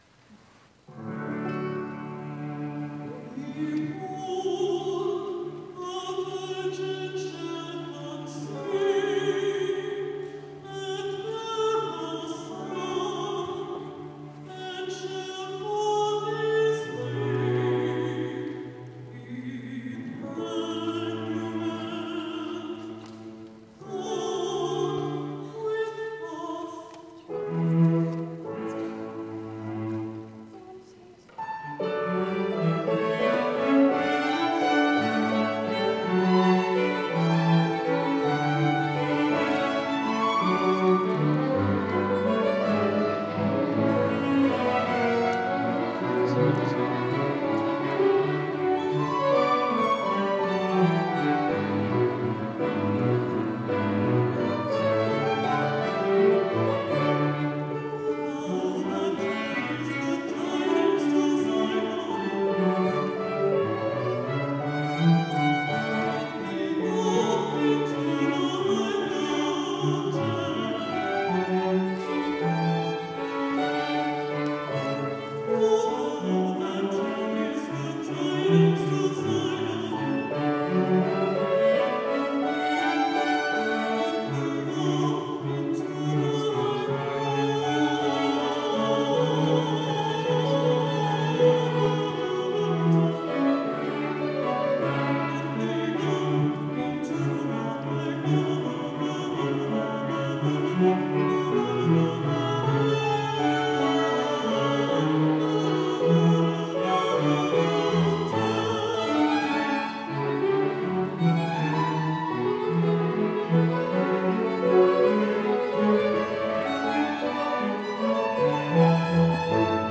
Cathedral Choir, Living Word, Angelorum, Cathedral Clergy Choir, Diocesan Choir, December, 2023